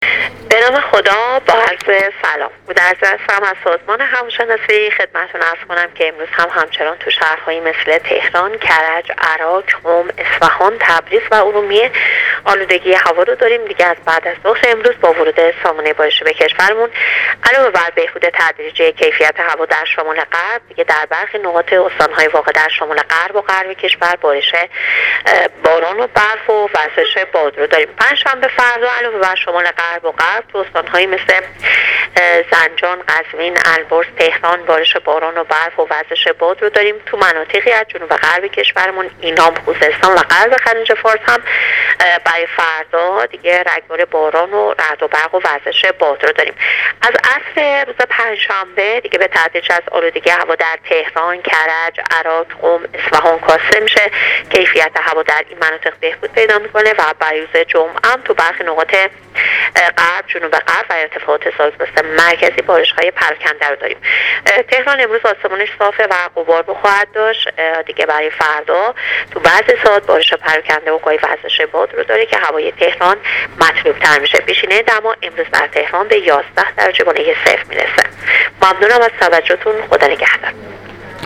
گزارش رادیو اینترنتی از آخرین وضعیت آب‌و‌هوای ششم آذر ۱۳۹۸